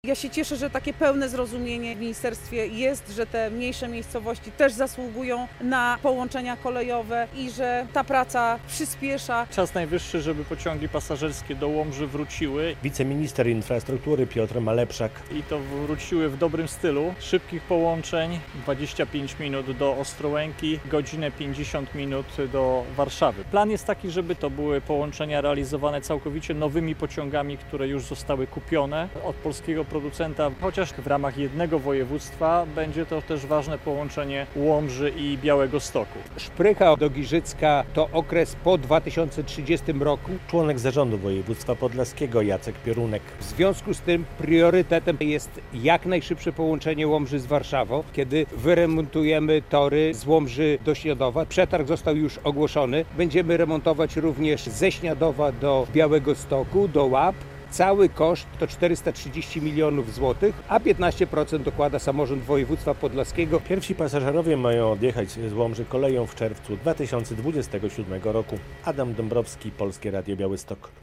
W czerwcu 2027 roku pierwsi pasażerowie z Łomży będą mogli jechać pociągiem do Warszawy – zadeklarował podczas konferencji prasowej wiceminister infrastruktury Piotr Malepszak.
Kolej w Łomży - relacja